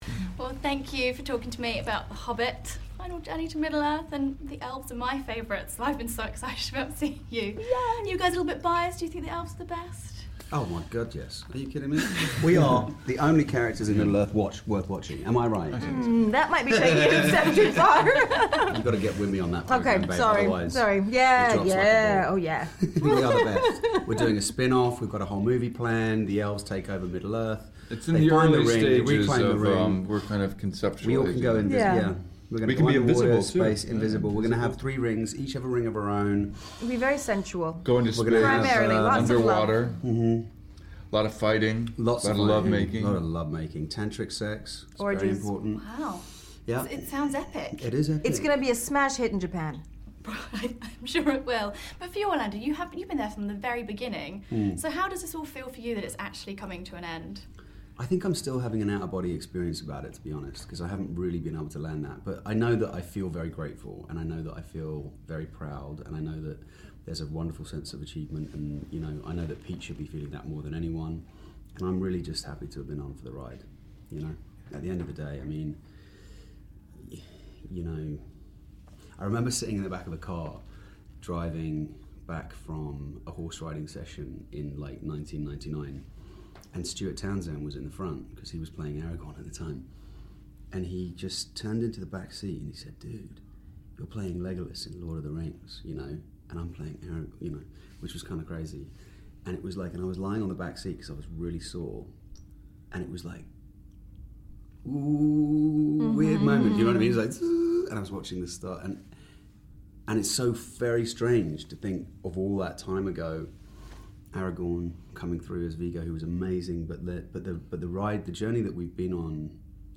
The Hobbit: The Battle of the Five Armies - The Elves Interview